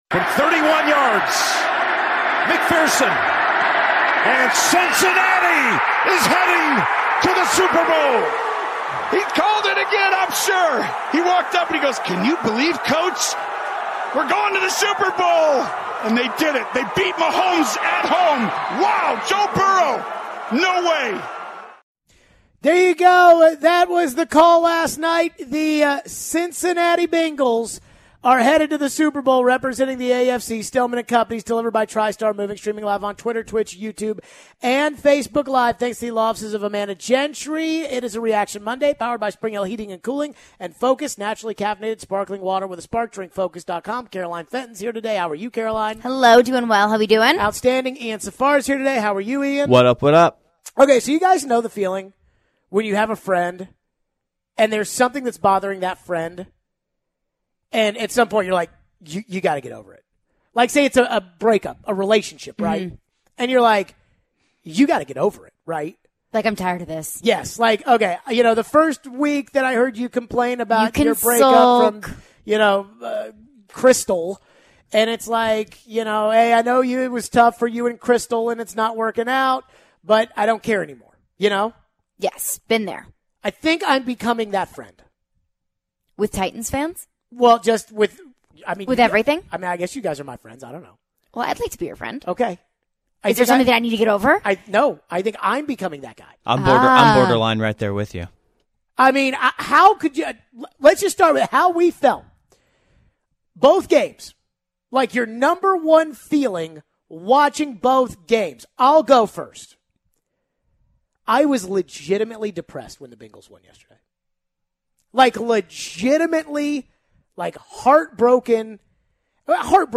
Plus your phones on the Titans and Tannehill.